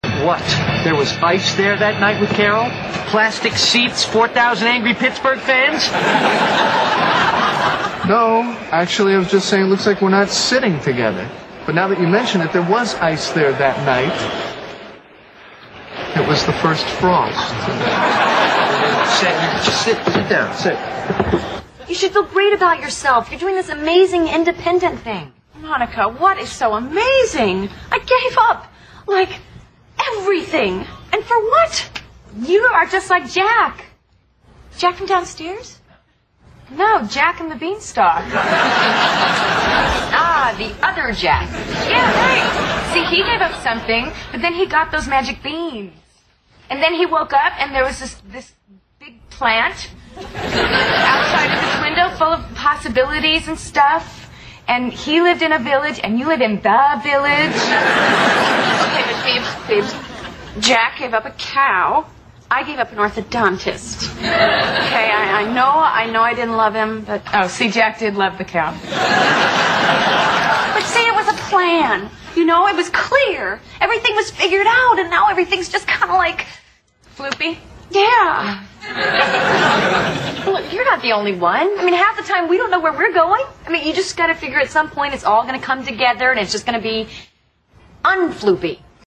在线英语听力室老友记精校版第1季 第39期:克林顿亲信助手(7)的听力文件下载, 《老友记精校版》是美国乃至全世界最受欢迎的情景喜剧，一共拍摄了10季，以其幽默的对白和与现实生活的贴近吸引了无数的观众，精校版栏目搭配高音质音频与同步双语字幕，是练习提升英语听力水平，积累英语知识的好帮手。